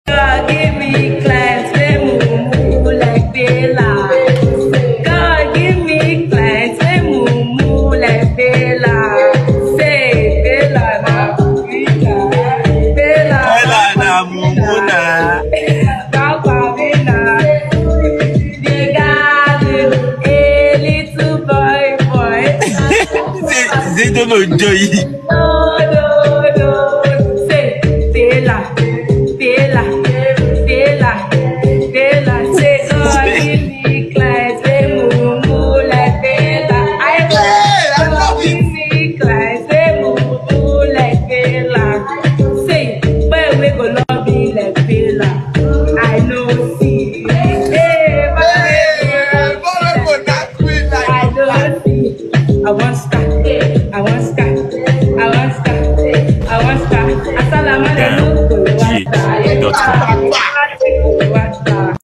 Tiktoker and freestyle vocalist